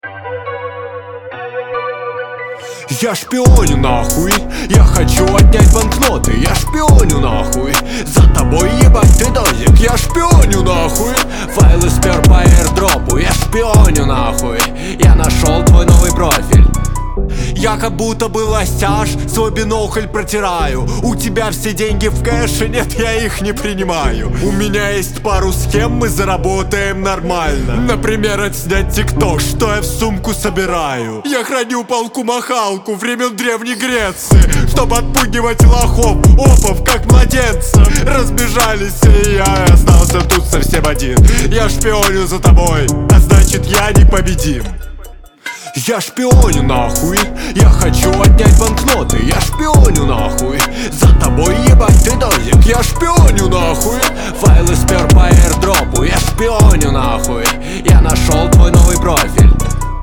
Русский Рэп